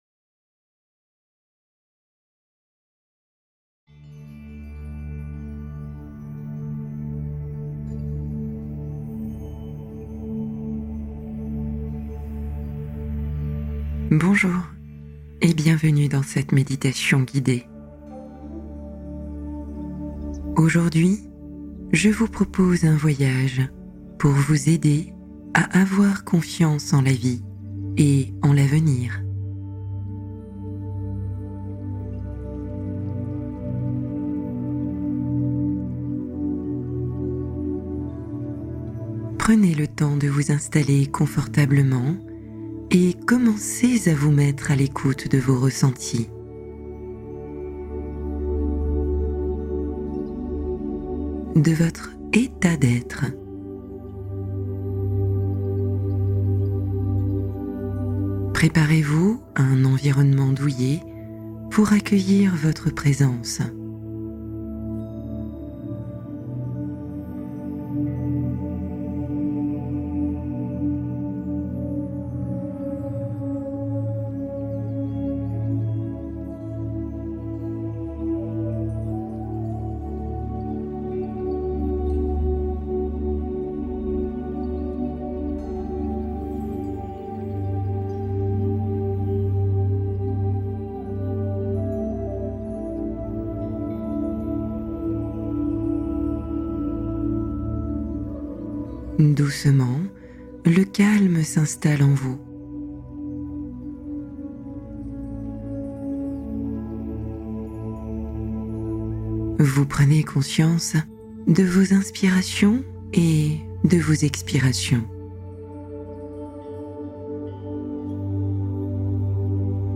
Nouvelle version améliorée : Faites confiance à la vie et à votre avenir | Méditation apaisante